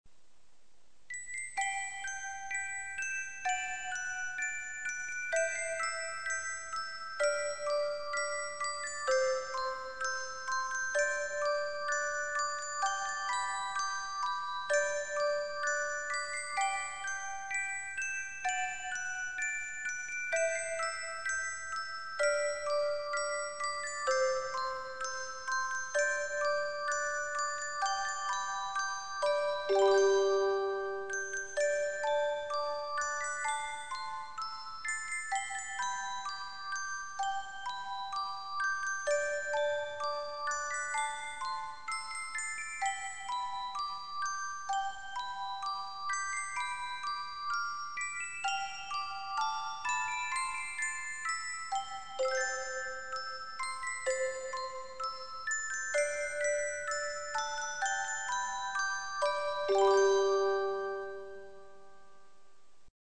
そのせいでＭＰ３版もＭＩＤＩ版もオルゴール・アレンジしか存在しないんですよ。